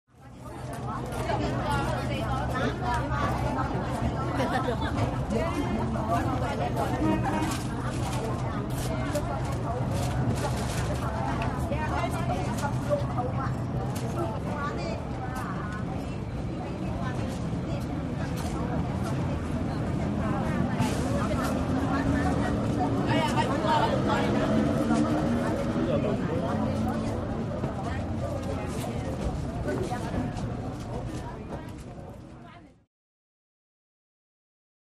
Walla, Chinese | Sneak On The Lot
Chinese Street Ambience With Pointed Lines And Traffic